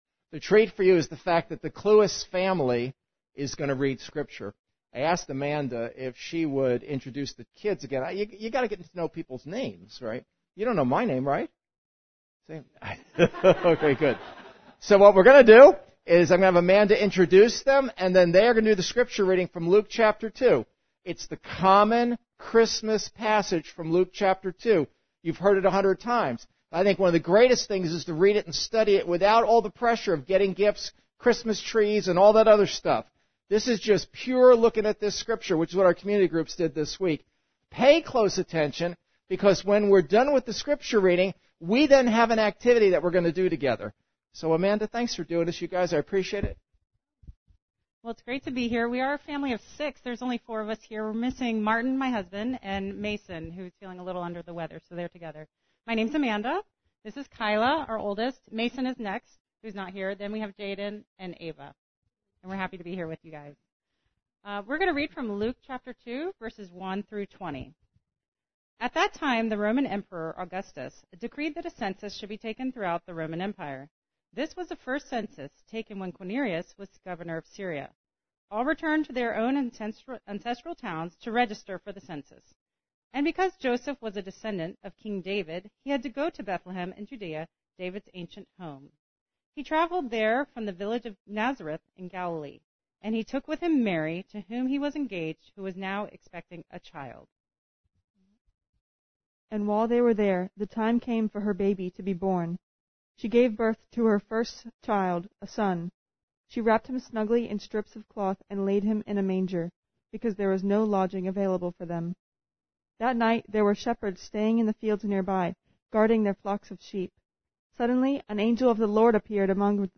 Luke 2:1-20 Service Type: Gathering Shepherds?!